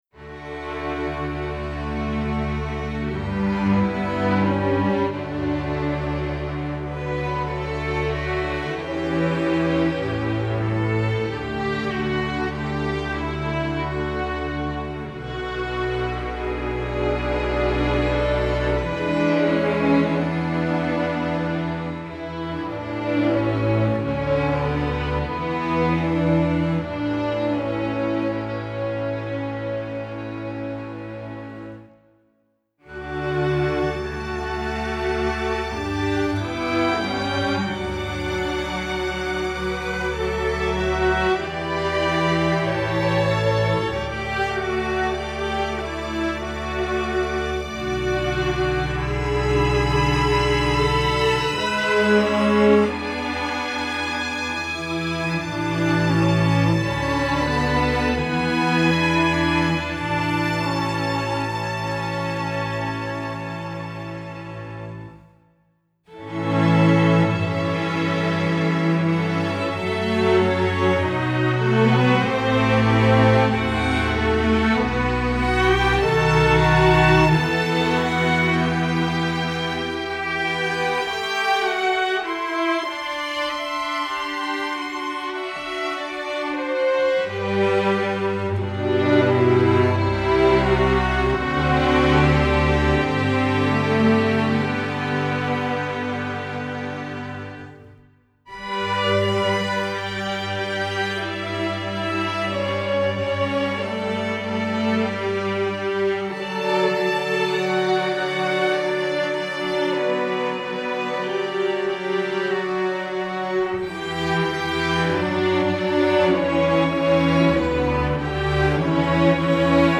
Instrumentation: string orchestra
masterwork arrangement